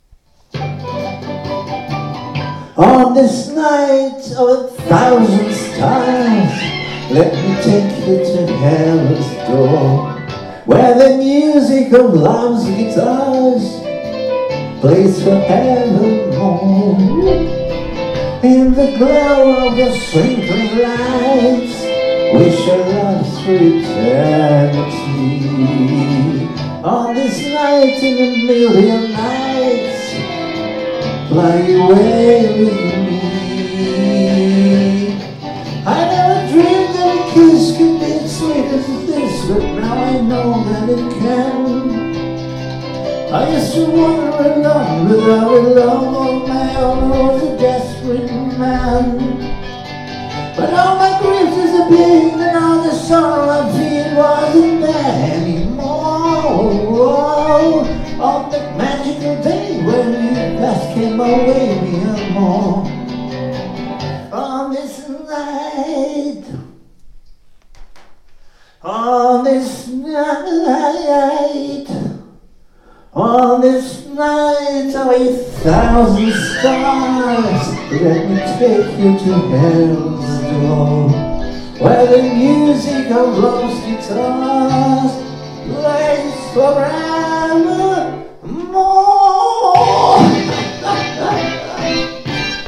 Минусовка из сети